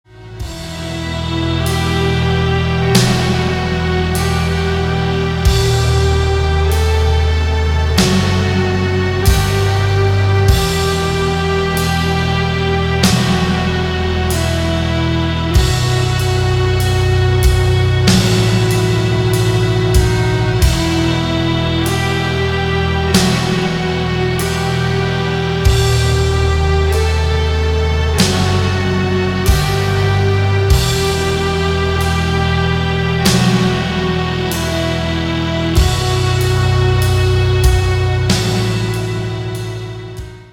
• Качество: 192, Stereo
без слов
Funeral Doom Metal